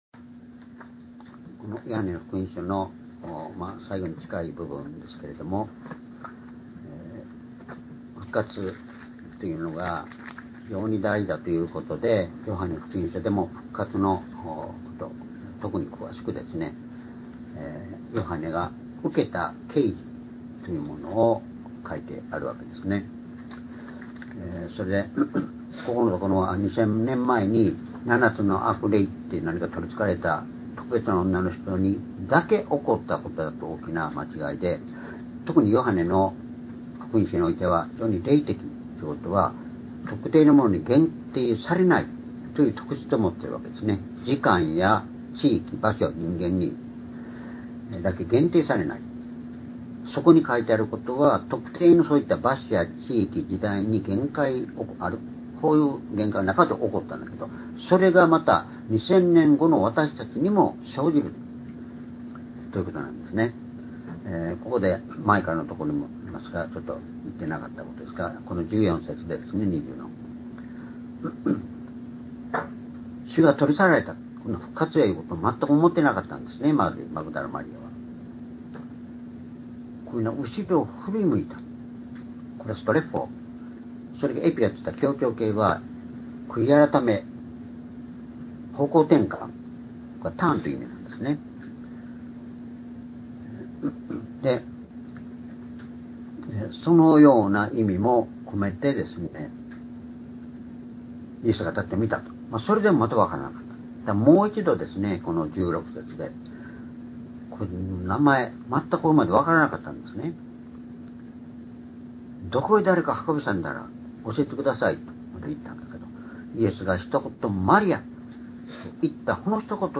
主日礼拝日時 2025年3月30日(主日礼拝) 聖書講話箇所 「私たちにとって『主を見る』とは」 ヨハネ20章16～18節 ※視聴できない場合は をクリックしてください。